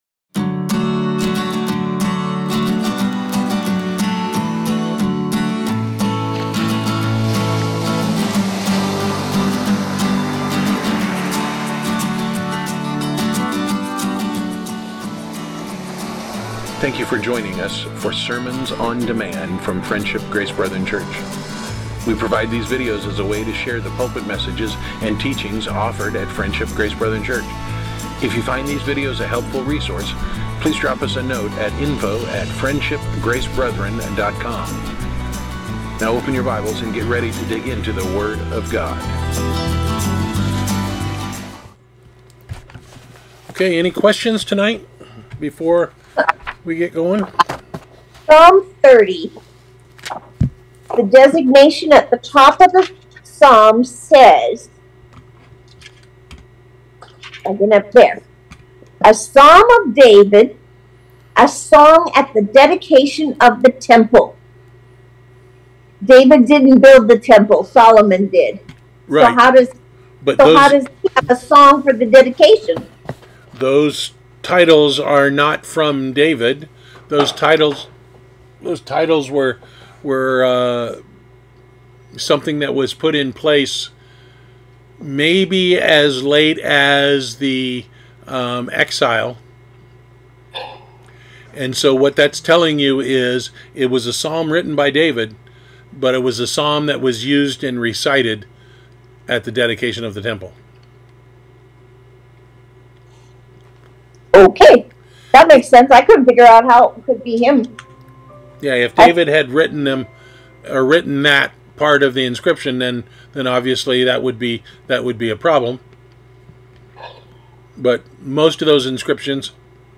Series: Weekly Bible Discussion